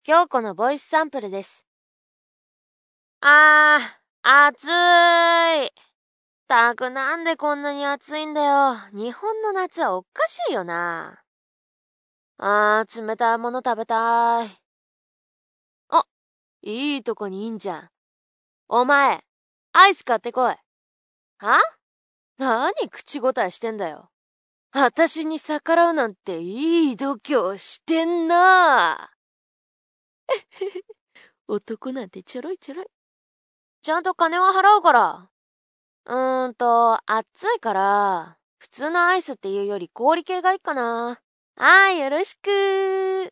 コメント 　サンプルはファイルサイズを軽くするために少し音質が悪くなってますので、実際はもう少しクリアだと思います。
強気Ｓ女の子。